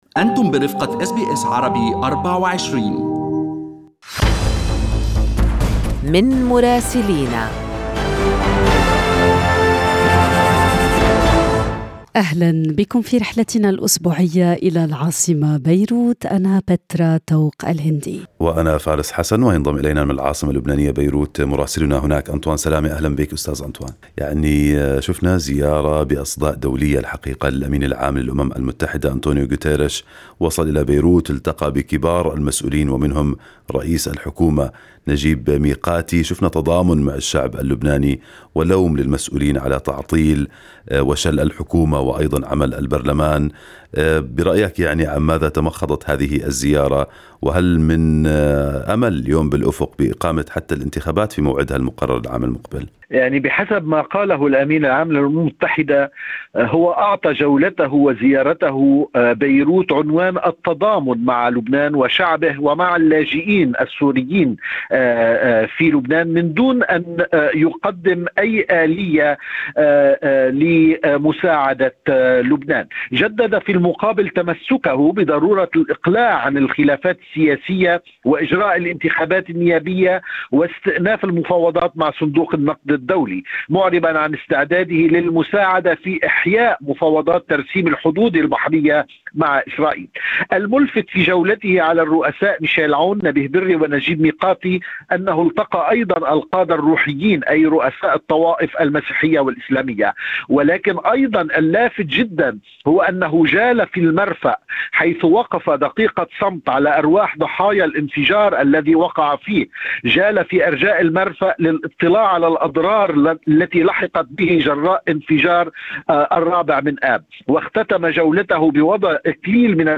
يمكنكم الاستماع إلى تقرير مراسلنا في لبنان بالضغط على التسجيل الصوتي أعلاه.